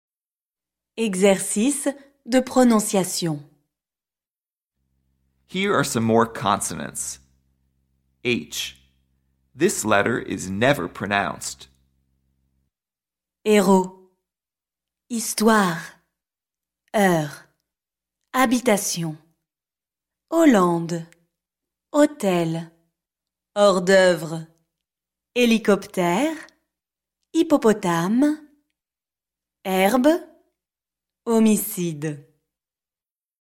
PRONONCIATION
h – This letter is never pronounced!